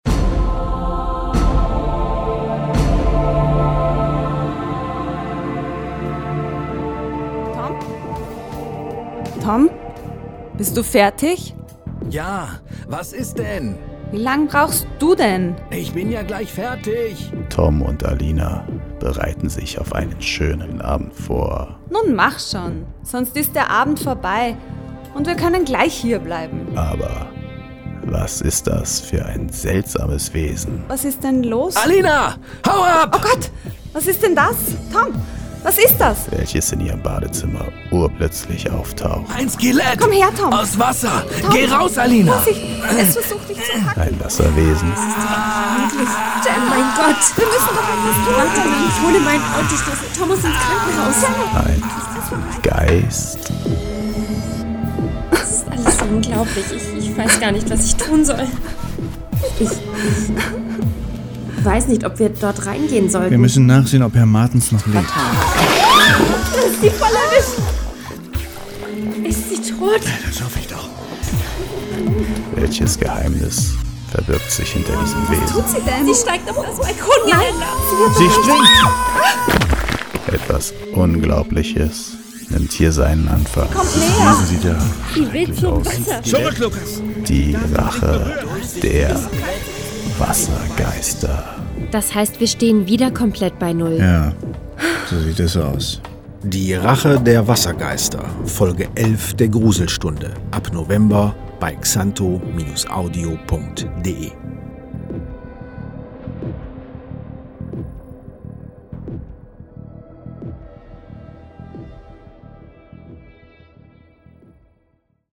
Gruselhörspiele, Grusel Hörspiele